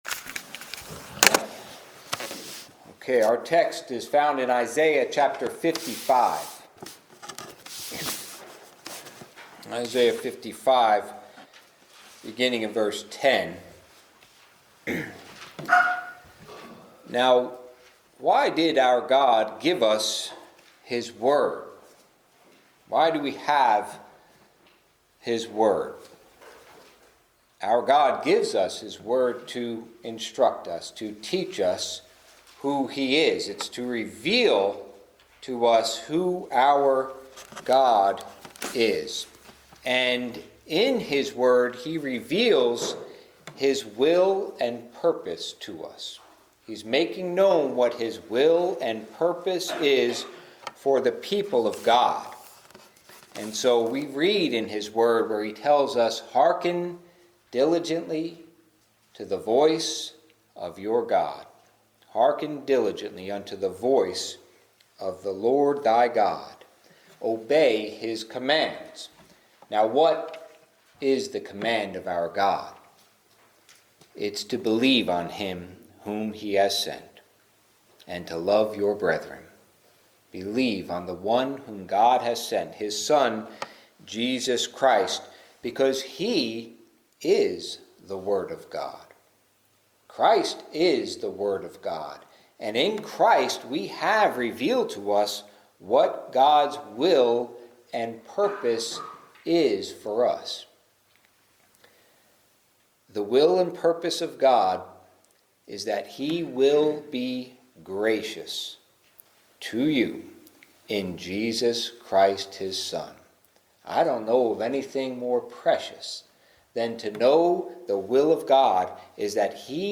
God's Word Goeth Forth | SermonAudio Broadcaster is Live View the Live Stream Share this sermon Disabled by adblocker Copy URL Copied!